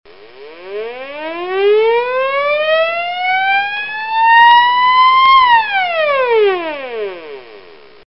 Звук сирены береговой обороны